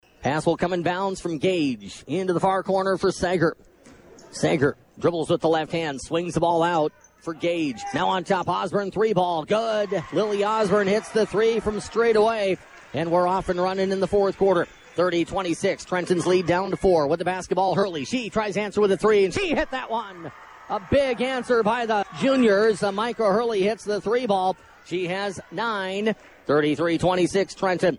High School Basketball